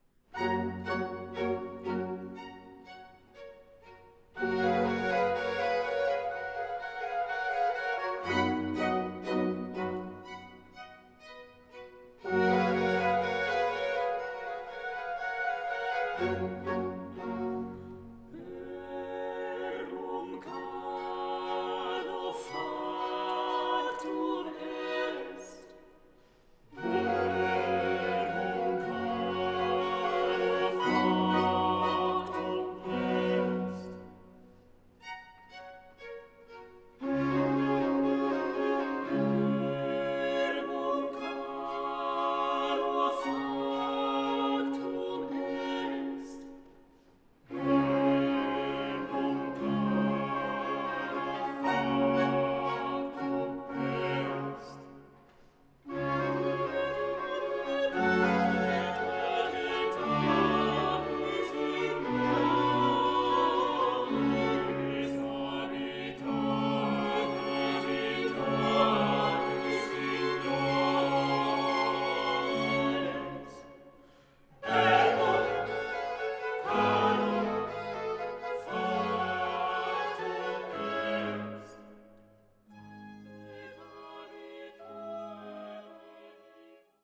una de las obras de referencia del Barroco musical español.